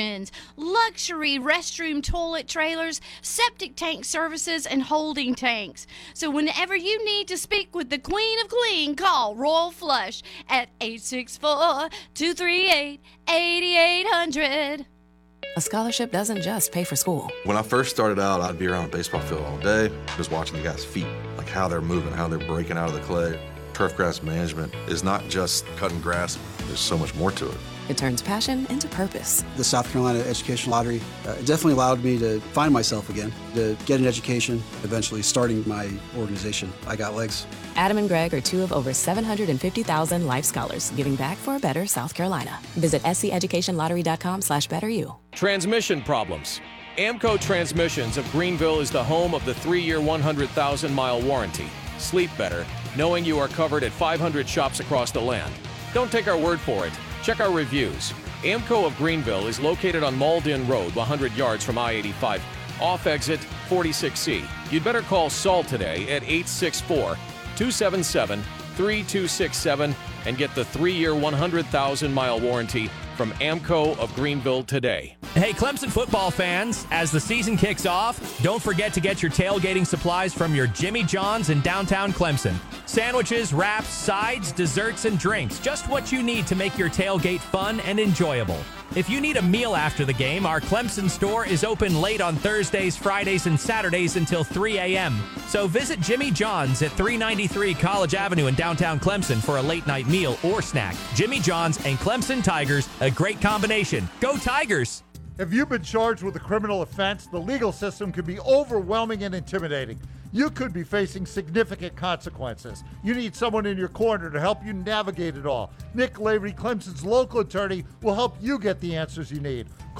He brings his fiery and passionate personality to the airwaves every day, entertaining listeners with witty comments, in depth analysis and hard-hitting interviews.